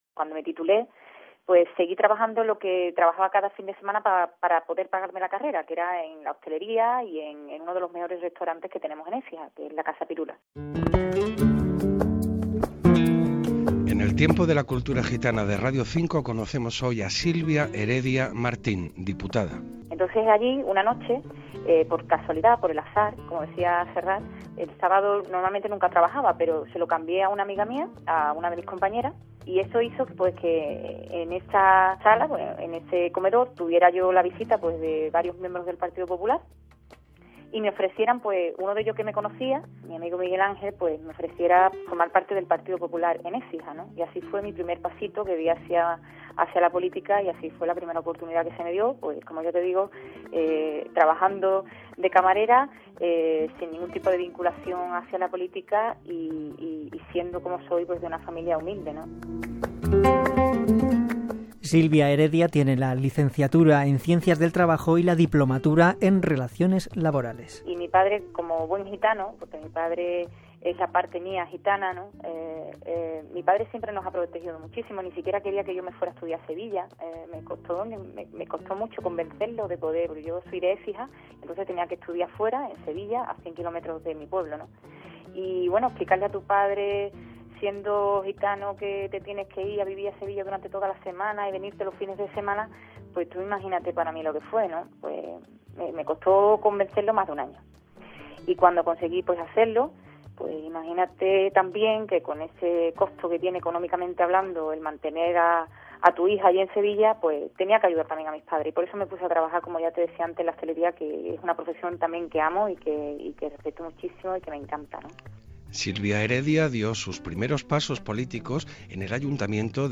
Entrevista a Silvia Heredia, gitana que va ser regidora de l'Ajuntament d'Écija i que era diputada al Congrés de Diputats pel Partido Popular